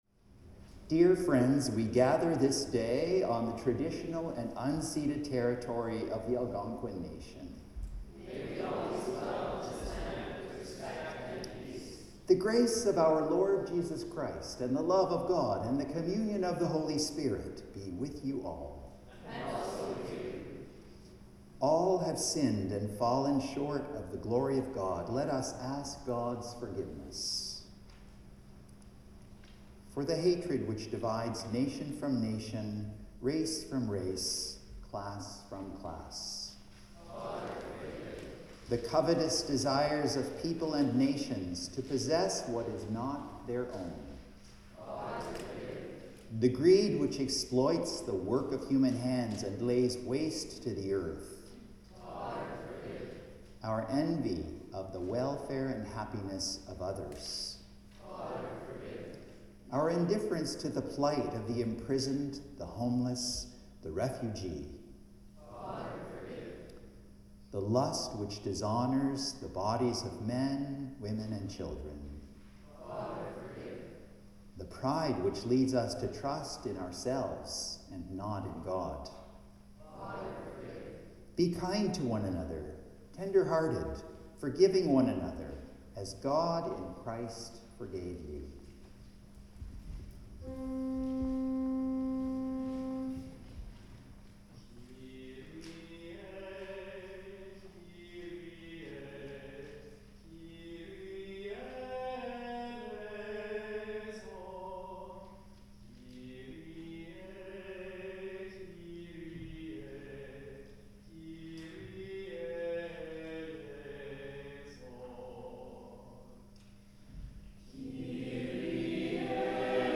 Sermons | St John the Evangelist
Prayers of the People The Lord’s Prayer (sung)